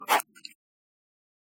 Sound FX
Writing in paper 19.wav